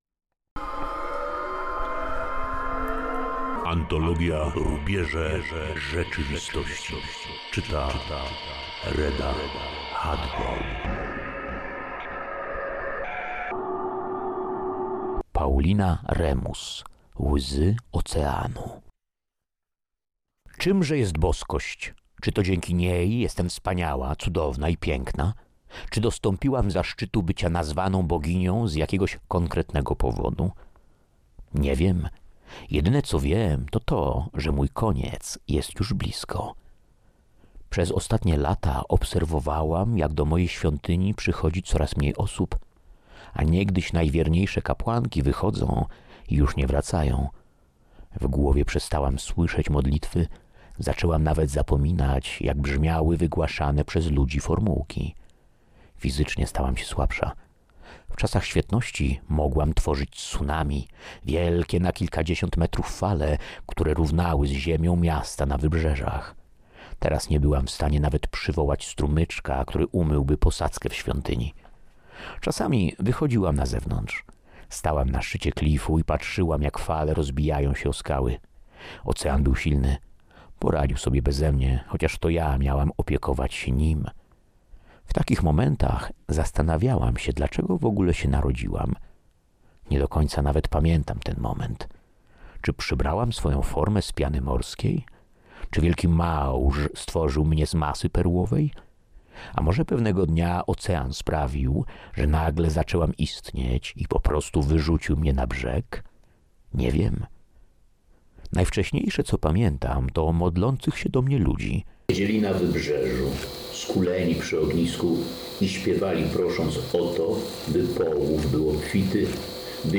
Na Bibliotekarium prezentujemy opowiadanie Łzy oceanu, autorstwa Pauliny Remus.